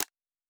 pgs/Assets/Audio/Fantasy Interface Sounds/UI Tight 26.wav
UI Tight 26.wav